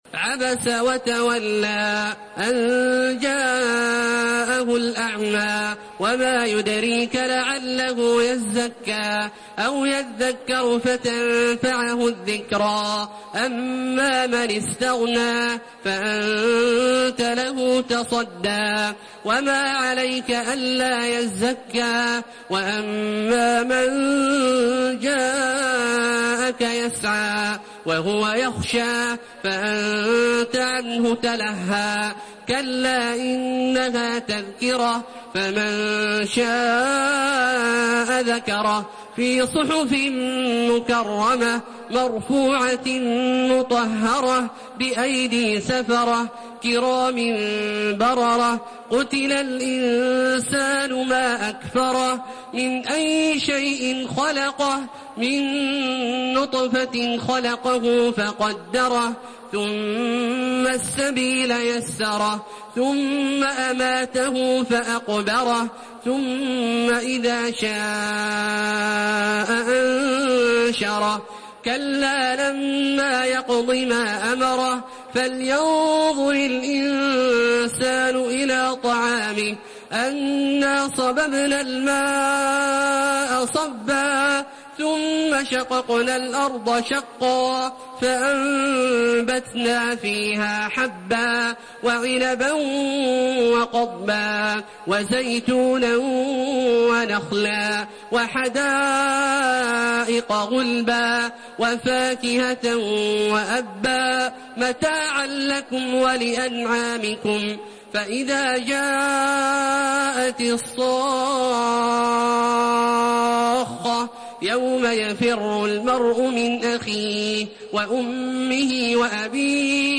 Surah Abasa MP3 in the Voice of Makkah Taraweeh 1432 in Hafs Narration
Murattal